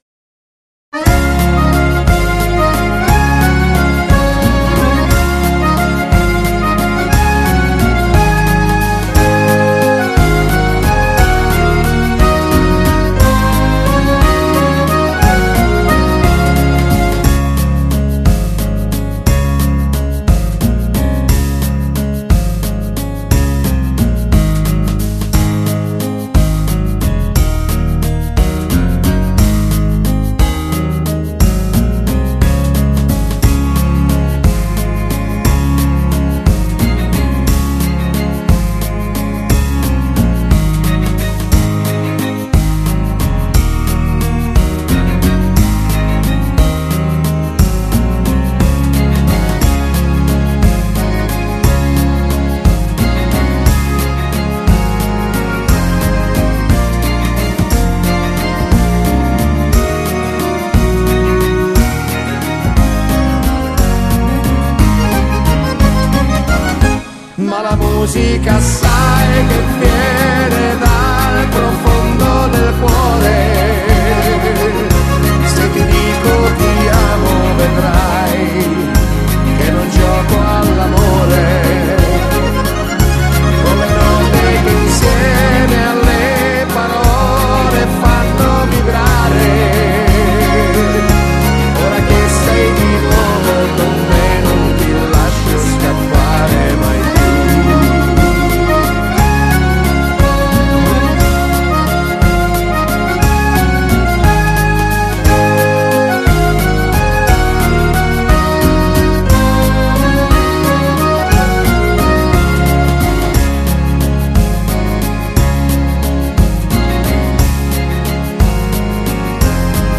Genere: Valzer
Scarica la Base Mp3 (3,53 MB)